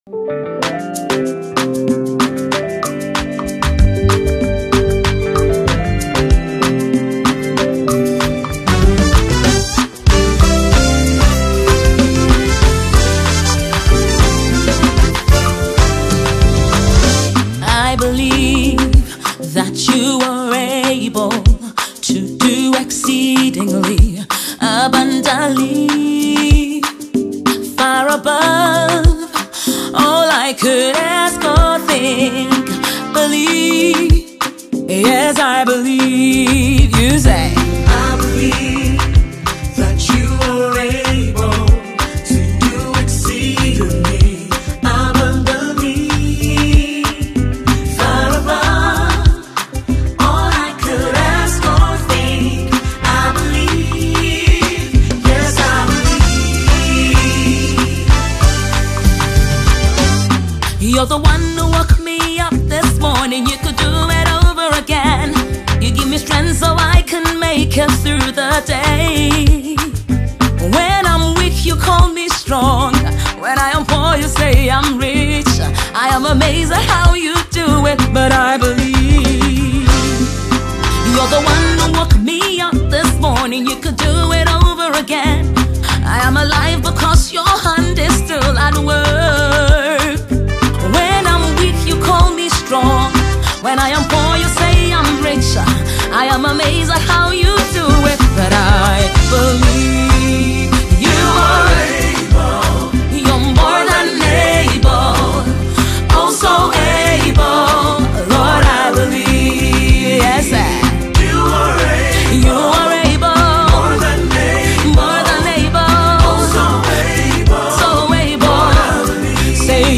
Ghanaian Award winning Gospel songstress